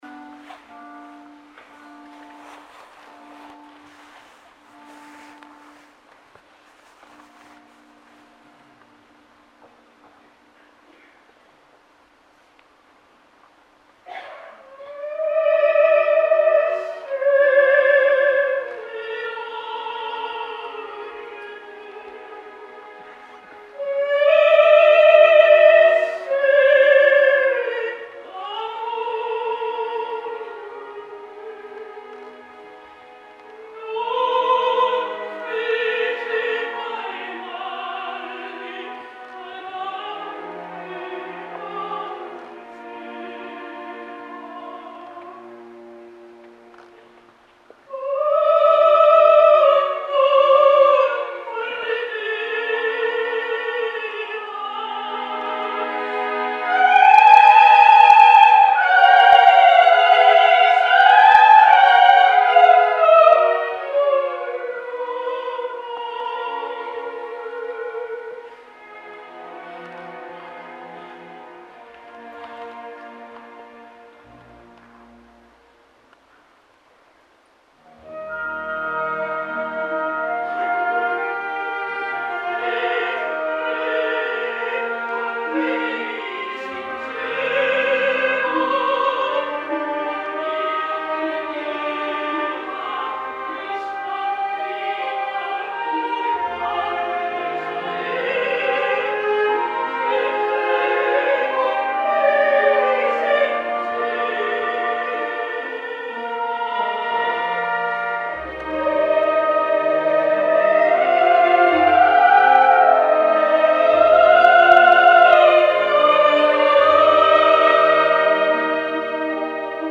Tosca, acte 2on, producció de Paco Azorín, Gran Teatre del Liceu, Sondra Radvanovsky i Ambrogio Maestri
Sondra Radvanovsky té una veu molt important, cada vegada de timbre més rar i amb alguns aguts crispats i altres de gloriosa contundència, amb un vibrato que podria ser fins i tot molest si al darrera no hi hagués una soprano de raça, d’aquelles que imposa personalitat i presència artística i vocal només trepitjar l’escenari, amb un domini del personatge que fa impossible no seguir-la  constantment, i això a Tosca és molt important i no crec que hi hagi avui en dia gaires sopranos que puguin fer gala d’aquesta fisonomia artística en un rol tan emblemàtic.
Malgrat el volum considerable i la projecció tan generosa,  és capaç d’arreplegar la veu en els moments més lírics i tendres, sense que aquesta boníssima projecció en surti perjudicada.  Òbviament el seu gran moment és el “Vissi d’arte” i la seva versió és per guardar-la a la memòria, amb un control de les intensitats emocionals admirable, quelcom imprescindible per fer d’aquesta ària, la gran ària.